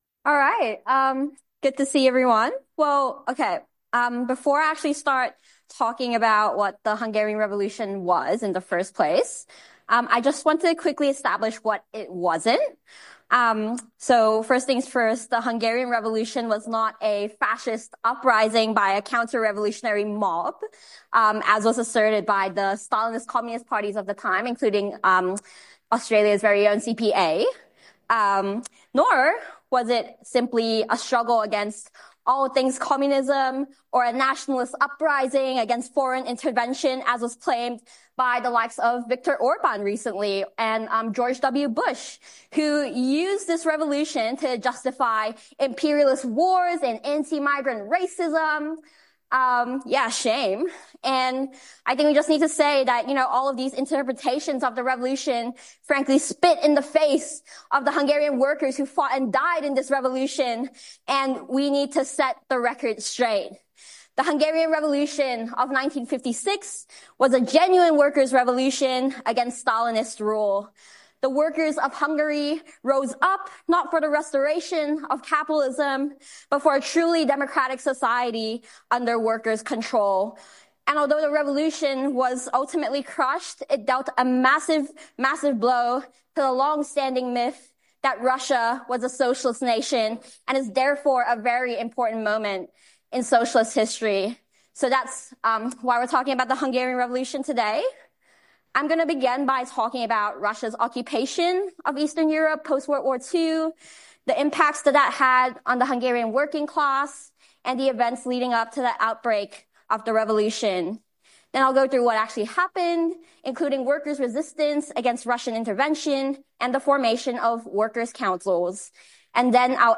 Socialism 2025 (Brisbane)